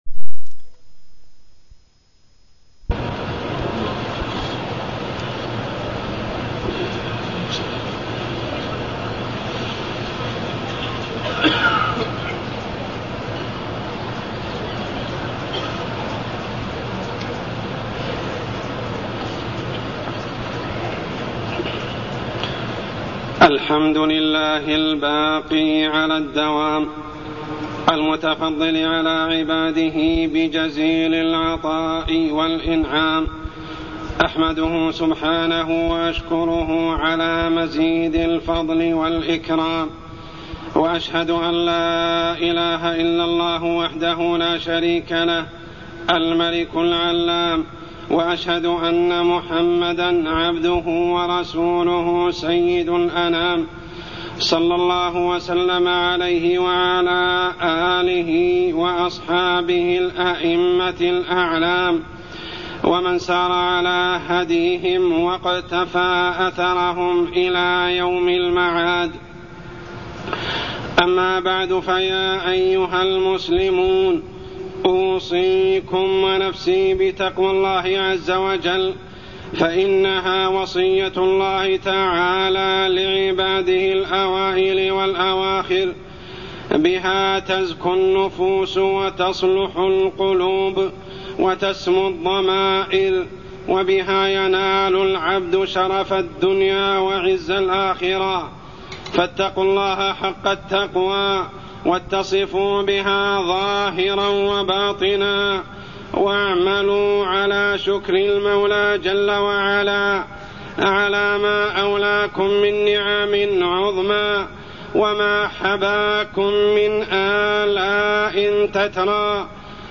تاريخ النشر ٣ شوال ١٤٢١ هـ المكان: المسجد الحرام الشيخ: عمر السبيل عمر السبيل أمارات قبول الطاعة